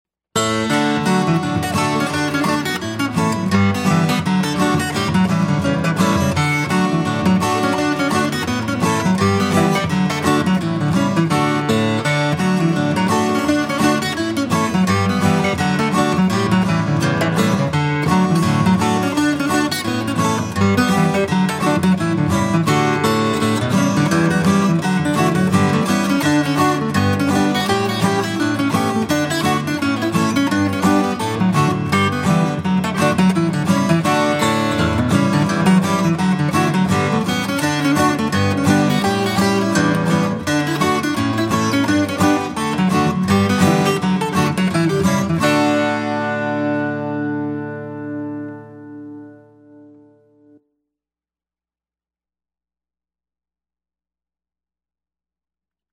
DIGITAL SHEET MUSIC - FLATPICK/PLECTRUM GUITAR SOLO